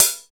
14 HAT 2.wav